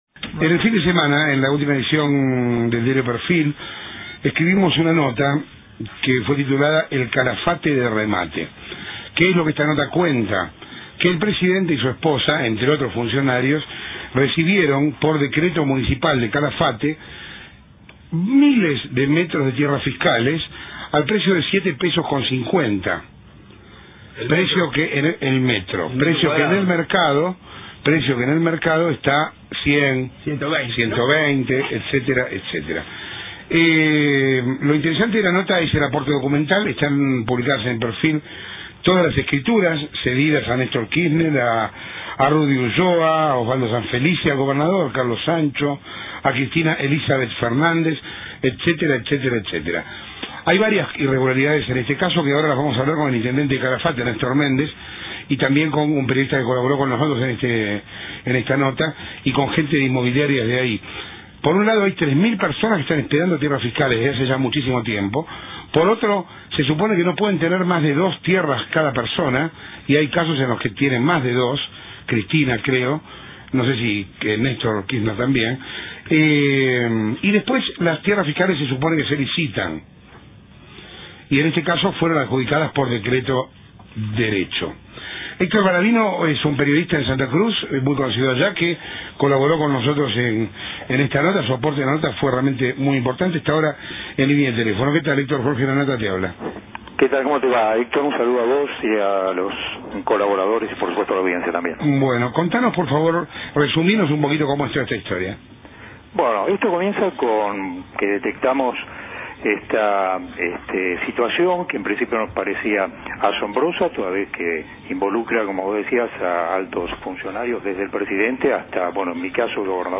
El audio antes referido incluye una entrevista al intendente de El Calafate, que contiene respuestas incre�bles.
Al art�culo le sigui� un antol�gico reportaje radial de Lanata al intendente de El Calafate , cuya escucha consideramos imperdible.